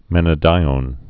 (mĕnə-dīōn)